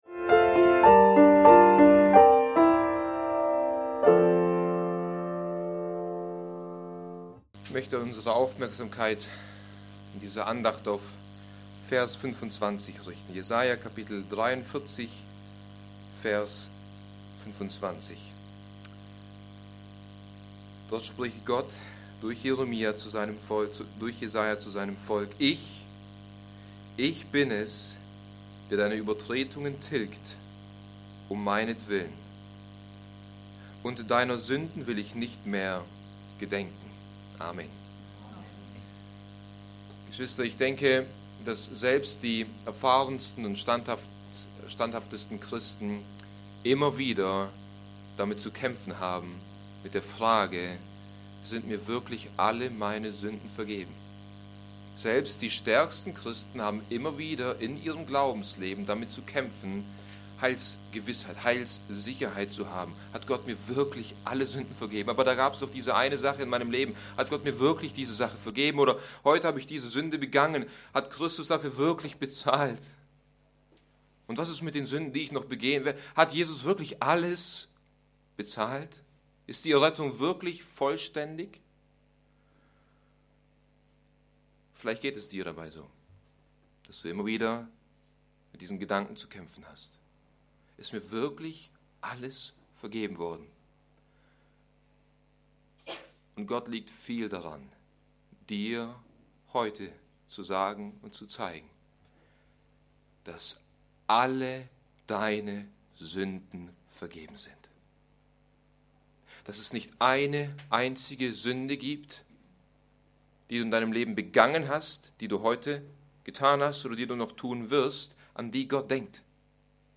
Allein aus Gnade - Bibeltreue Predigten der Evangelisch-Baptistischen Christusgemeinde Podcast